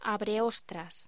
Locución: Abreostras
voz